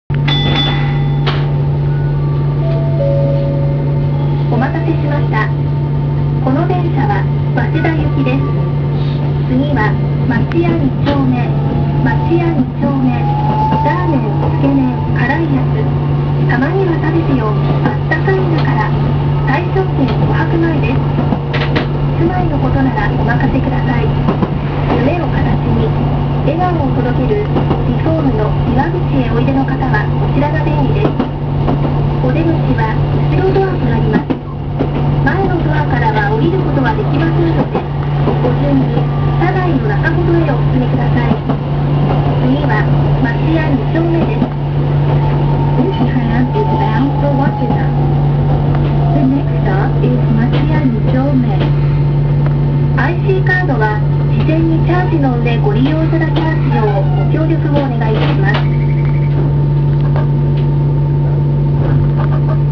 〜車両の音〜
・9000形走行音
【都電荒川線】町屋駅前→町屋二丁目（1分2秒：342KB）
8800形や8900形と走行音自体は変わりません。